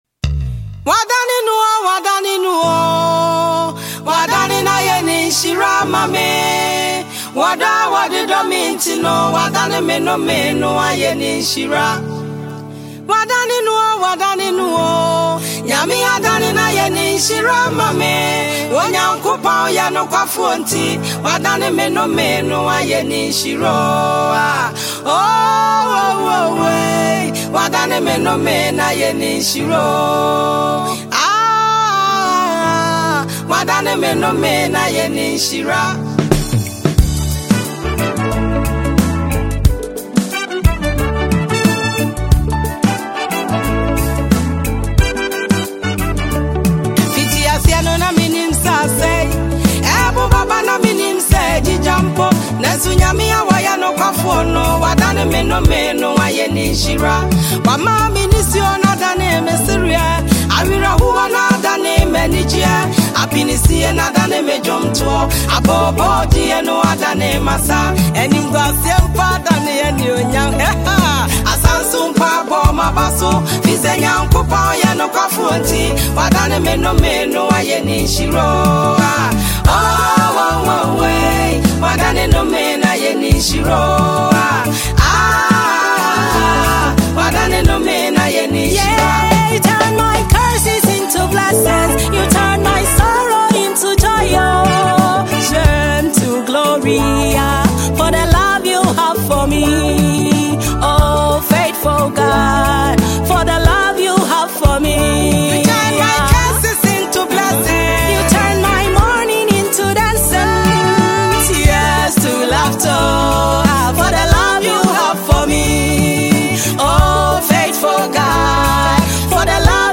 Ghanaian gospel musician